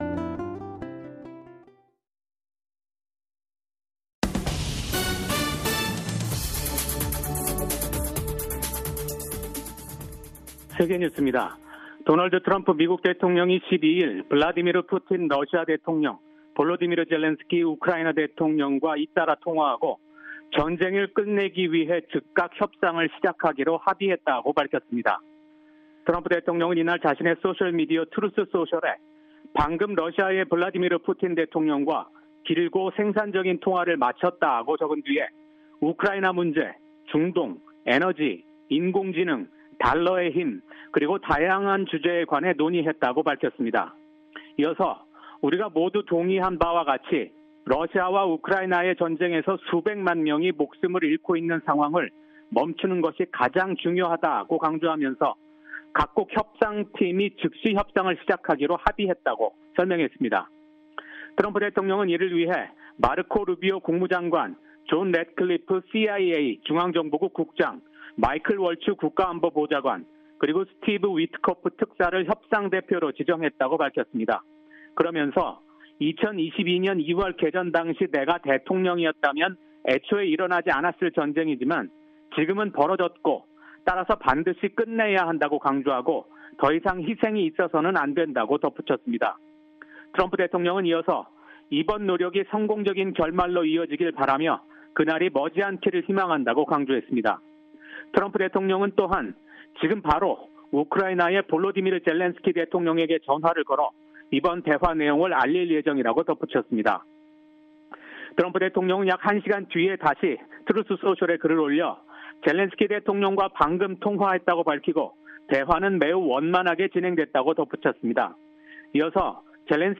VOA 한국어 아침 뉴스 프로그램 '워싱턴 뉴스 광장'입니다. 도널드 트럼프 미국 대통령이 또 다시 김정은 북한 국무위원장과의 정상회담을 추진할 것임을 시사했습니다. 미국인 여성이 북한 정보기술(IT) 인력의 위장 취업을 도운 혐의를 인정했다고 미 법무부가 밝혔습니다. 미국 연방수사국(FBI)이 공개 수배 중인 대북제재 위반자가 최근 급증 양상을 보이고 있습니다.